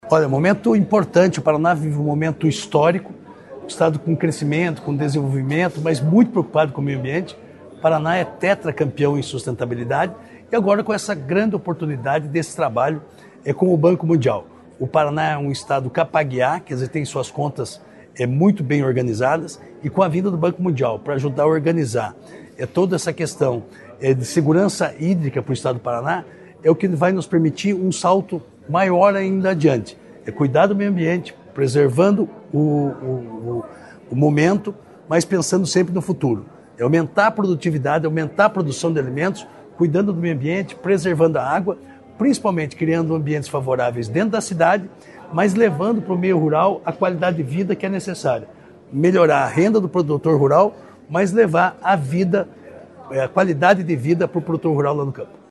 Sonora do secretário Estadual da Agricultura e do Abastecimento, Marcio Nunes, sobre as reuniões do Governo com o Banco Mundial pelo Programa de Segurança Hídrica